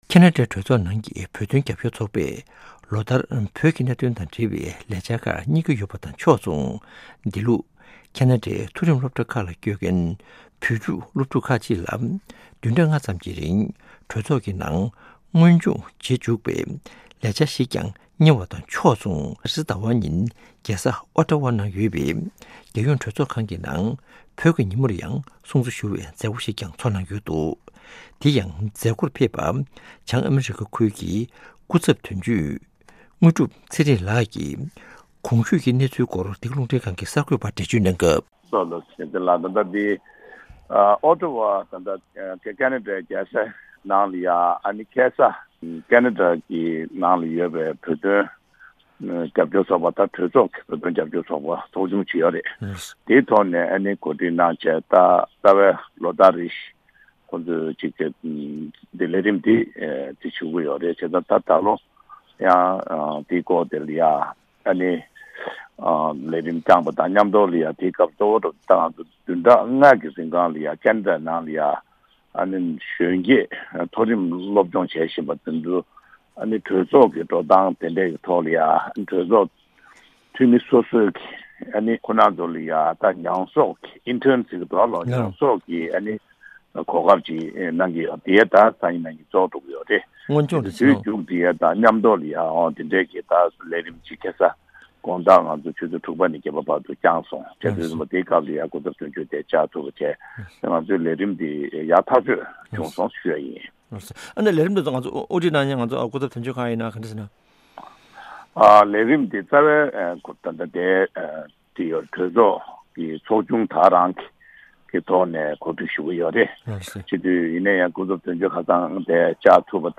བཅར་འདྲི་ཞུས་ནས་གནས་ཚུལ་ཕྱོགས་བསྒྲིགས་གནང་ཡོད།།